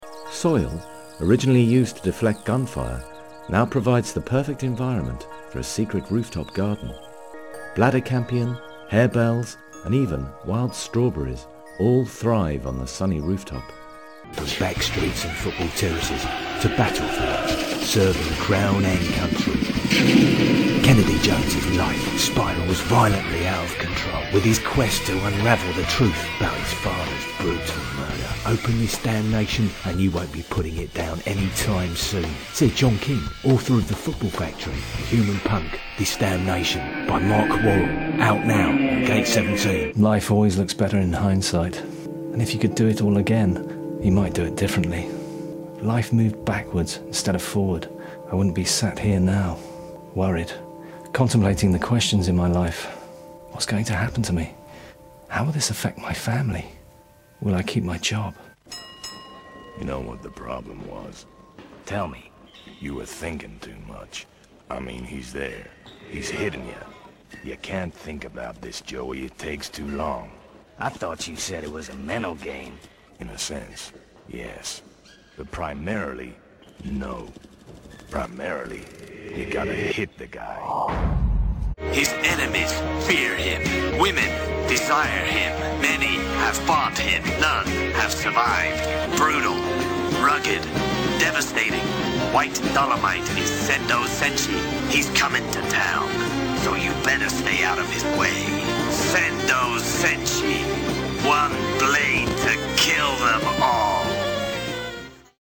Male
English (British)
Adult (30-50)
He has an assured vocal delivery, with a clear, professional edge.
Main Demo
Narration
All our voice actors have professional broadcast quality recording studios.